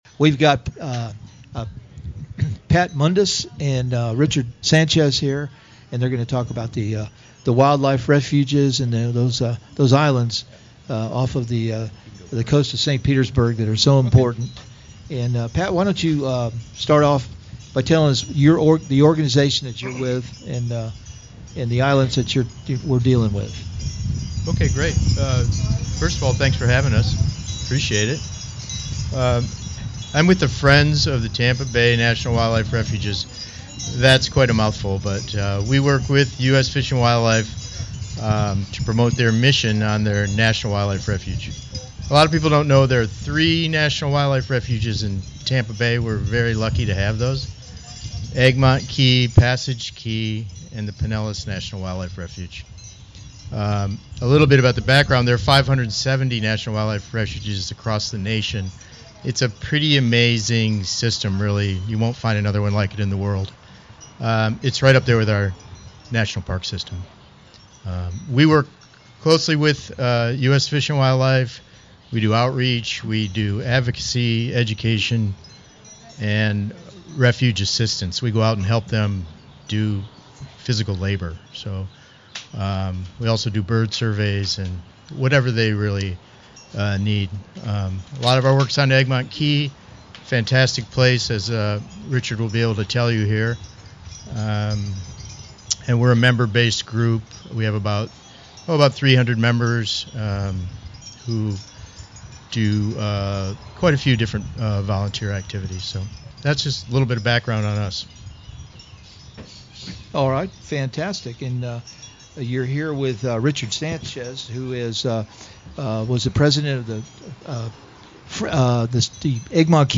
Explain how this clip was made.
Live broadcast from the Craftsman House Gallery.